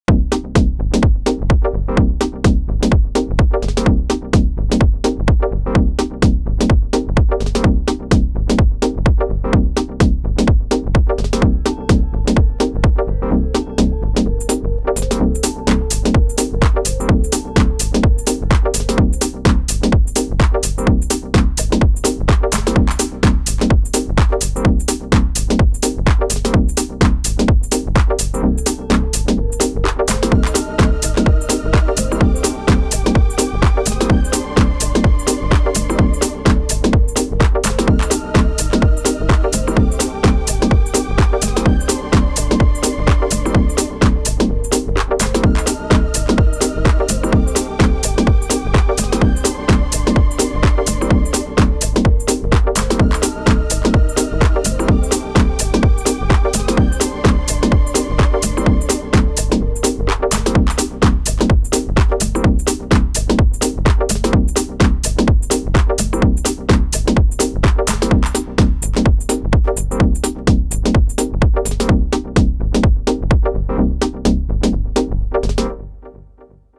Little house groove on the Syntakt.